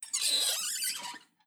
ClosetClosingSound.wav